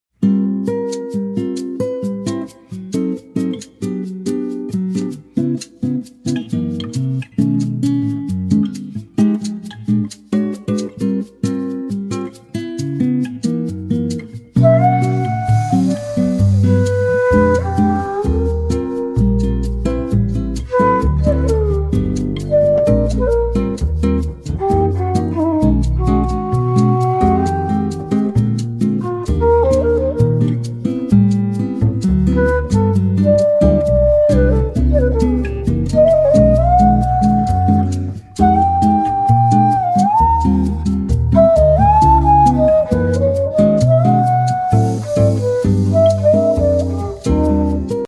Genre: Jazz.